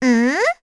Cecilia-Vox_Think_b.wav